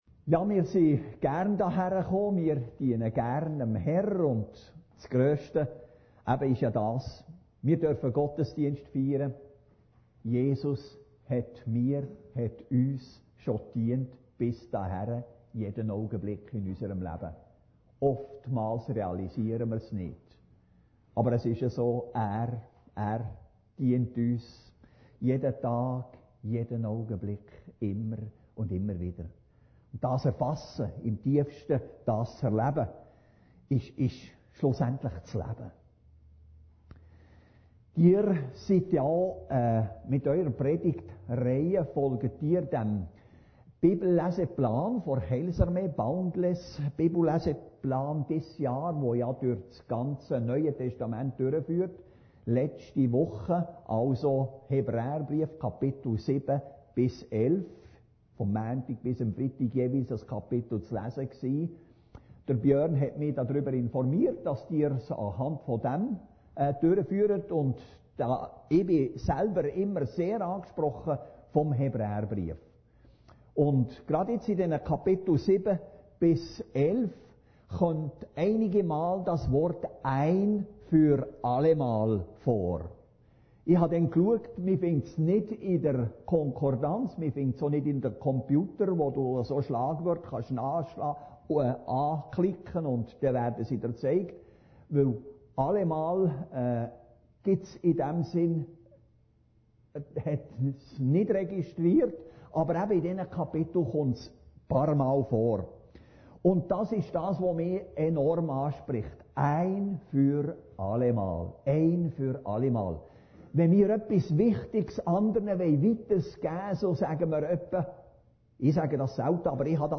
Predigten Heilsarmee Aargau Süd – ein für allemal